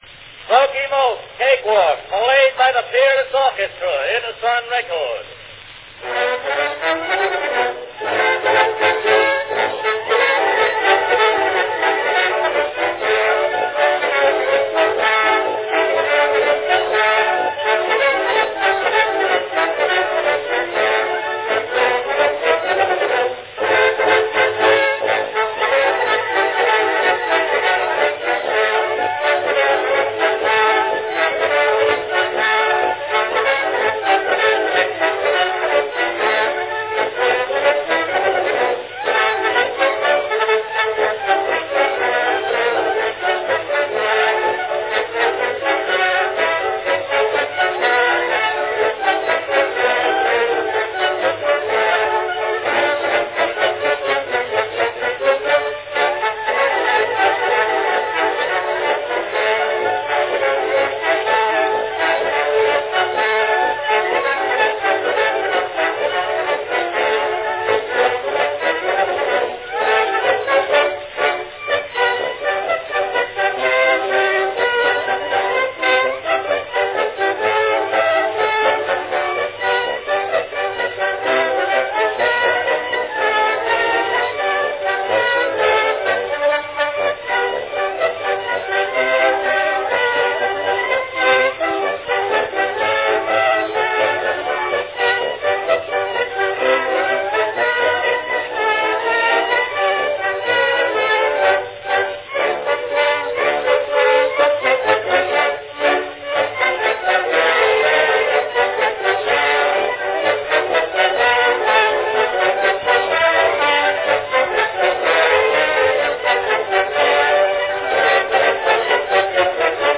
Category Orchestra
Performed by Peerless Orchestra
Announcement "Smoky Mokes (Cakewalk) played by the Peerless Orchestra.  Edison record."
Prepare yourself for 2 minutes of unbridled good cheer captured on wax:
Perfectly suited for hopping about (when no one's watching).
Cakewalk, on the other hand, is characterized by a lilting, singable treble line of one or two notes, narrow in range, in relatively longer note values accompanied by simple chords of three notes or less, and usually one-note basses in an interminable oom-pah pattern.